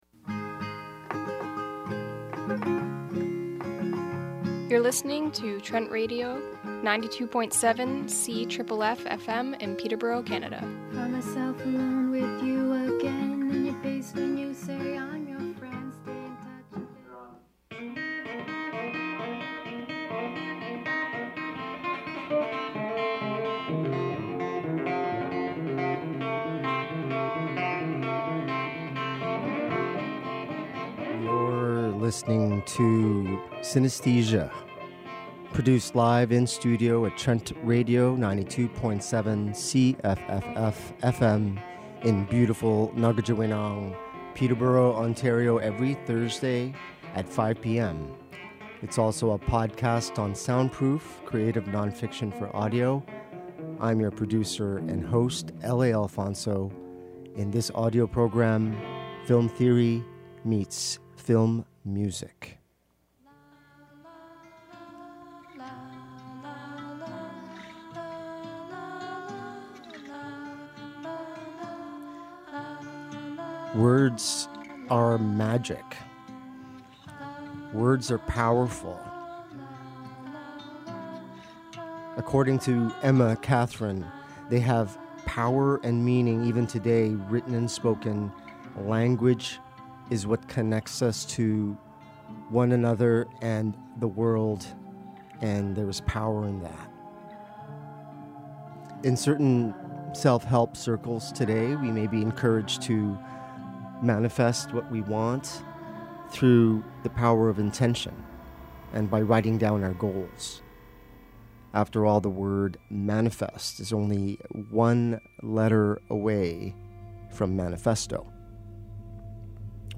Cinesthesia 08 Original Broadcast March 7, 2024 RADIO PLAYLIST 1.